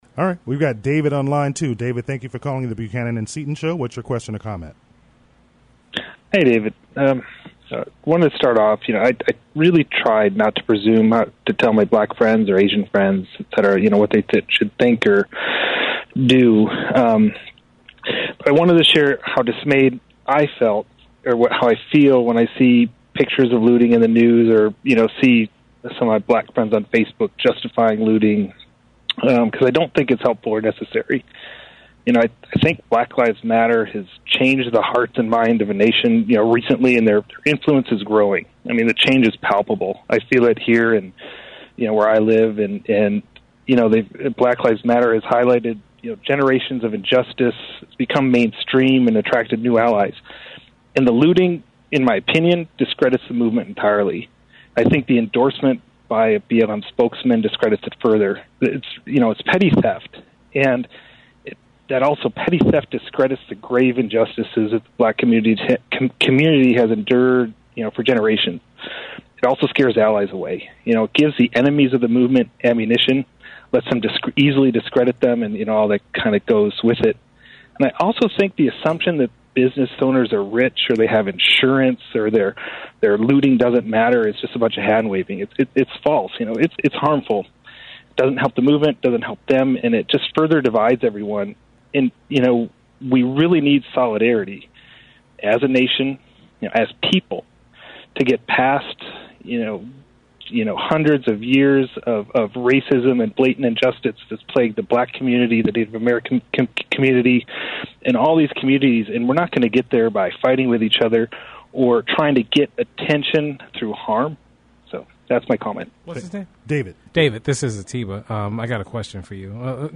While I don’t usually share individual calls from the show, this one made an impression on me.